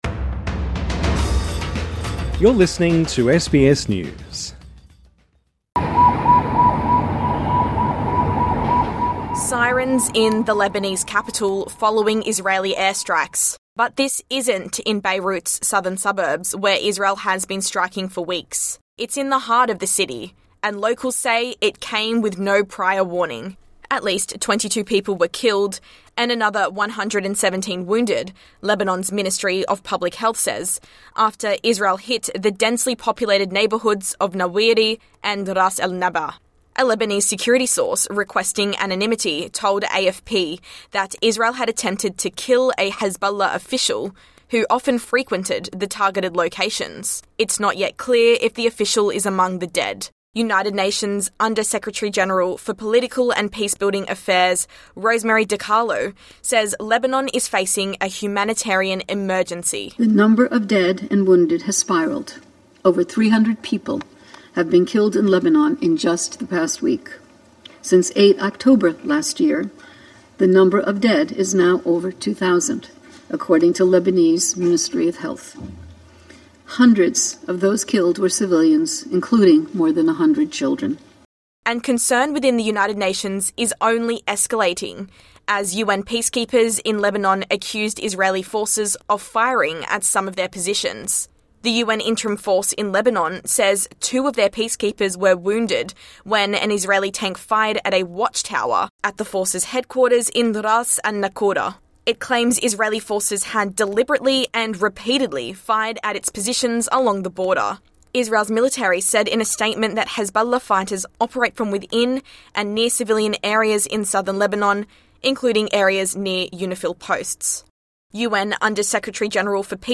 Listen to Australian and world news, and follow trending topics with SBS News Podcasts TRANSCRIPT Sirens in the Lebanese capital following Israeli airstrikes.